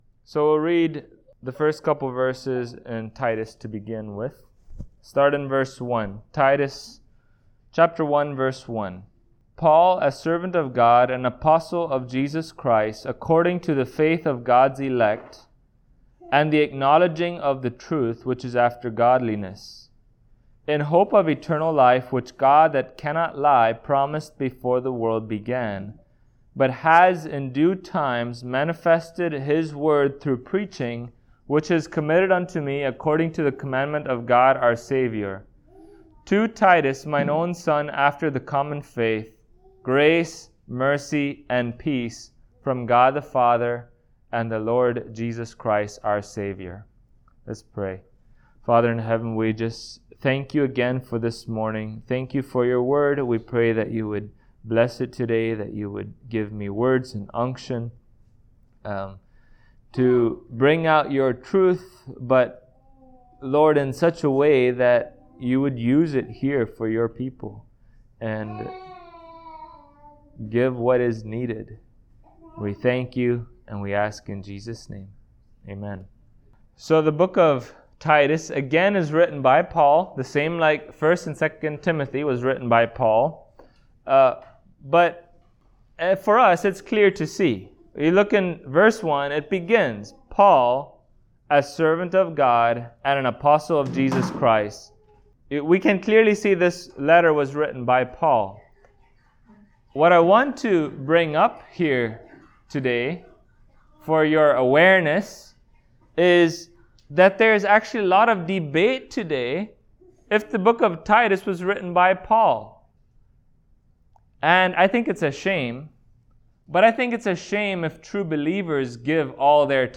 Titus Passage: Titus 1:1-4 Service Type: Sunday Morning Topics